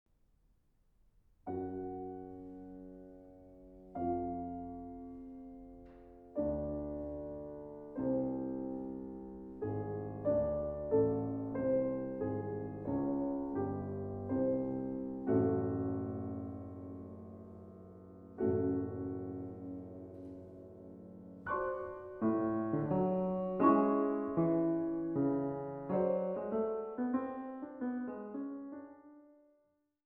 Klavier
selten zu hörende virtuose Klavierwerke
huldigen der Eleganz des italienischen Belcanto
für Klavier eingerichtet